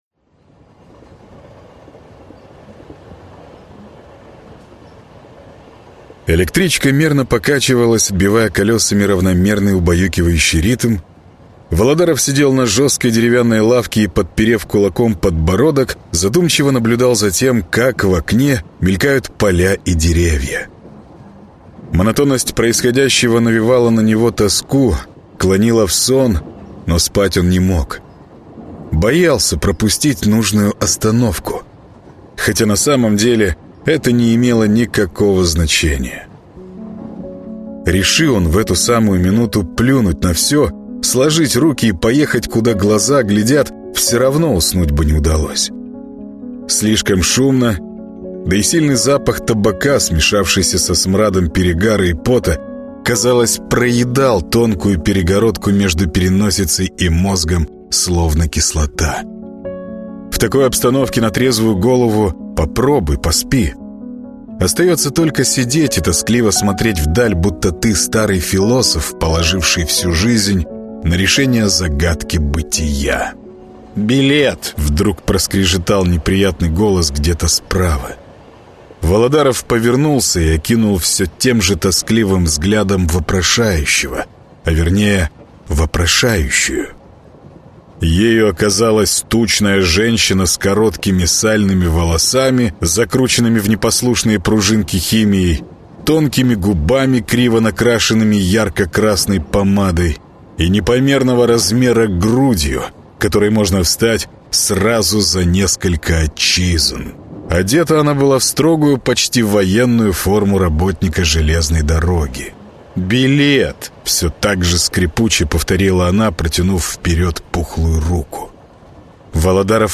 Аудиокнига Каменка. Мёртвое село | Библиотека аудиокниг